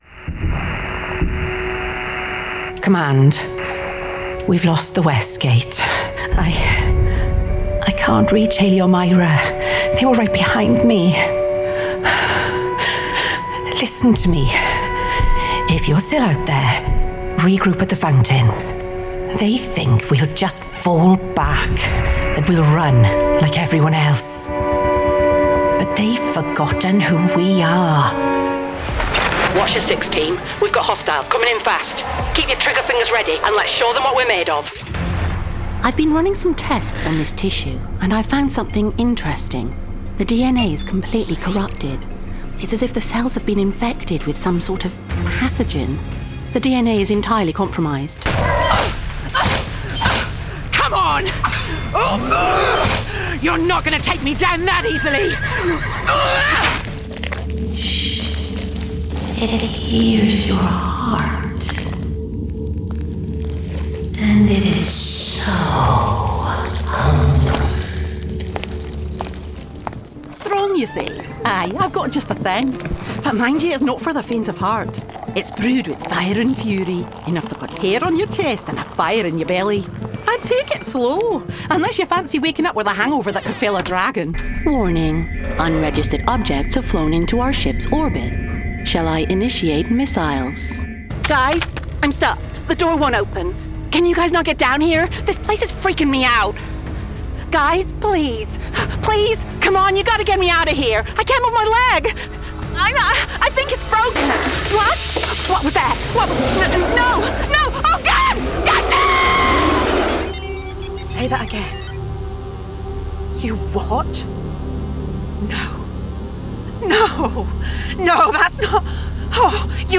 Gaming Showreel
Female
Playful